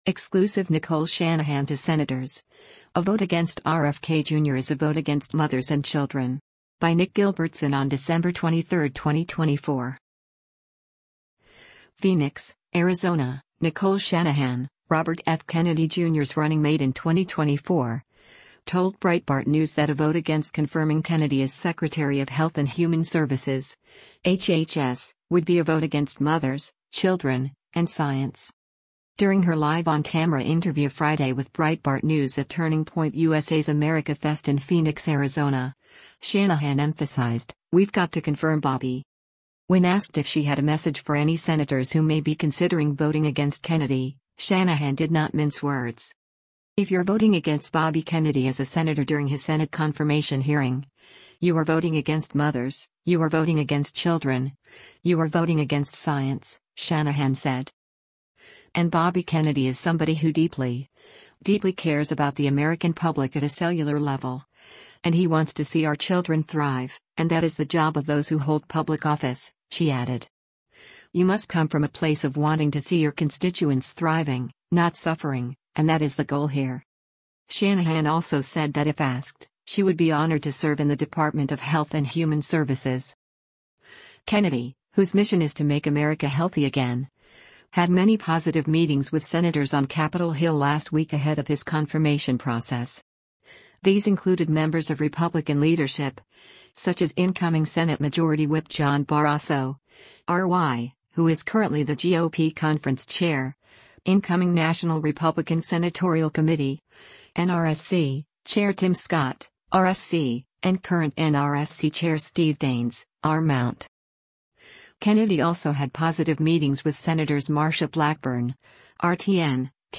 During her live on-camera interview Friday with Breitbart News at Turning Point USA’s AmericaFest in Phoenix, Arizona, Shanahan emphasized, “We’ve got to confirm Bobby.”